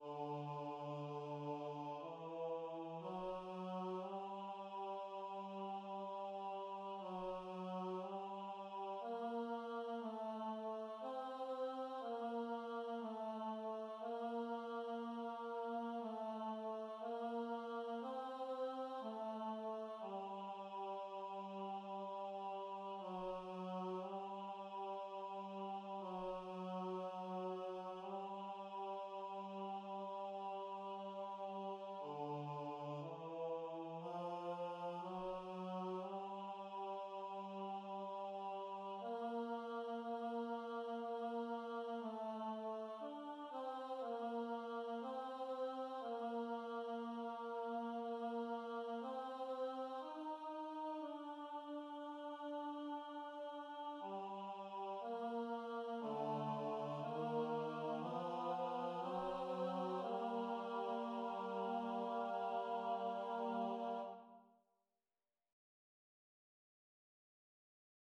Tenori